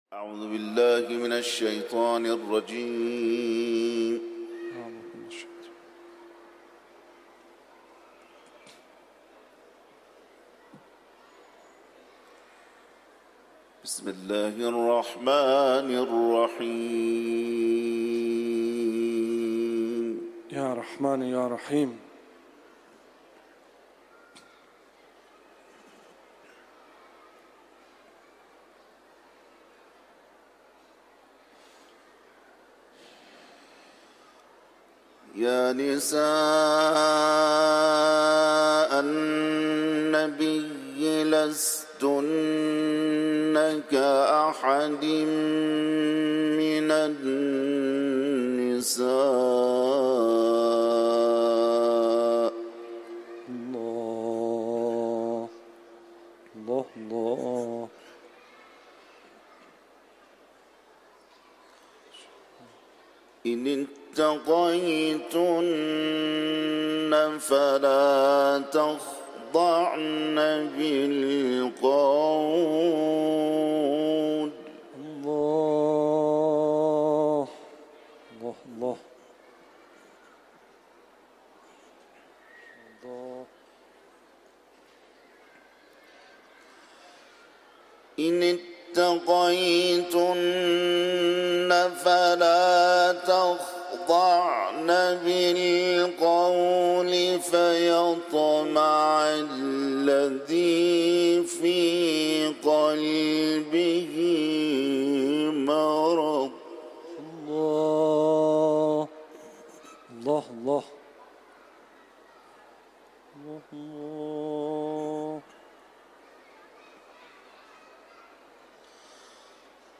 Kur’an tilaveti
tilaveti